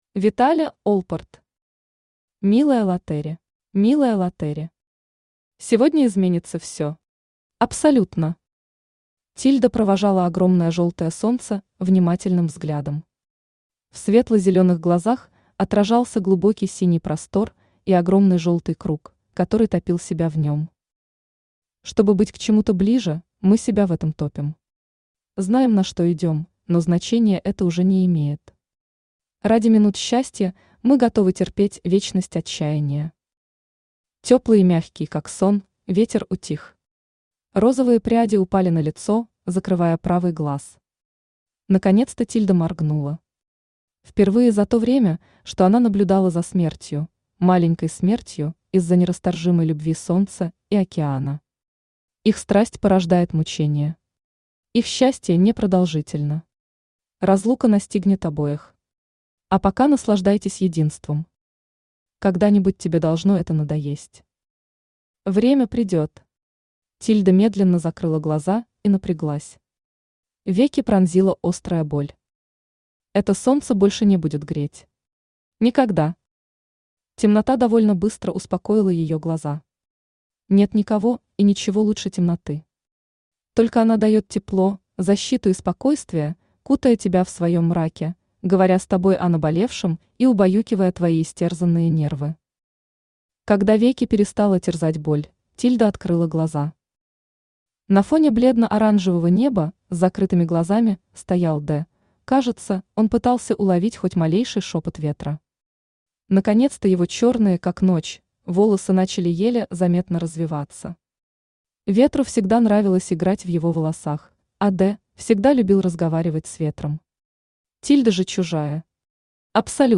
Аудиокнига Милая Лотери | Библиотека аудиокниг
Aудиокнига Милая Лотери Автор Виталя Олпорт Читает аудиокнигу Авточтец ЛитРес.